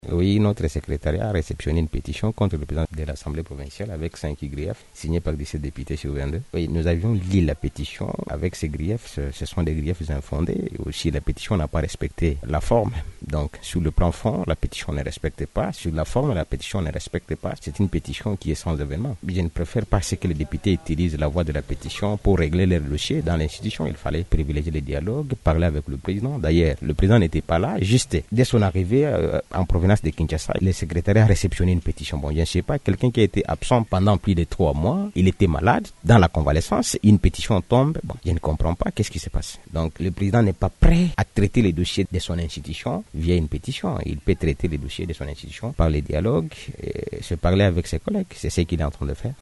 Réactions des parties concernées